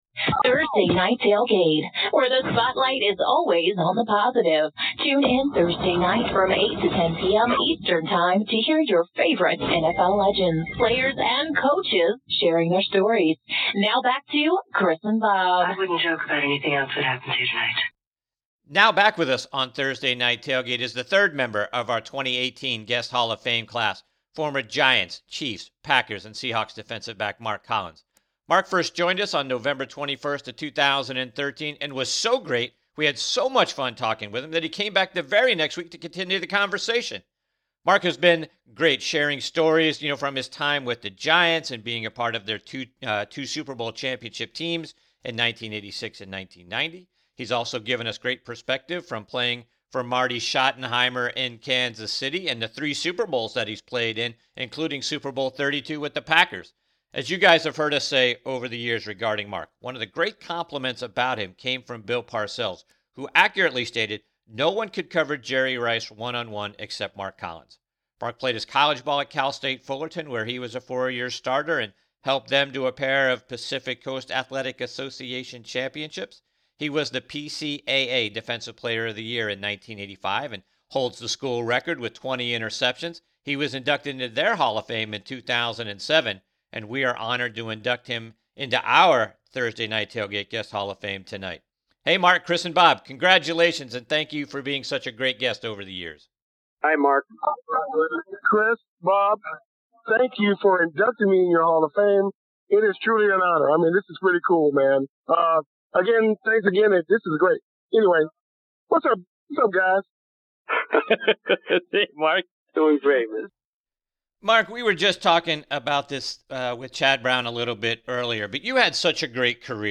Former Giants 2 time Super Champion, Chiefs, Packers & Seahawks DB Mark Collins joins us on this segment of Thursday Night Tailgate NFL Podcast.